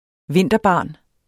Udtale [ ˈvenˀdʌˌbɑˀn ]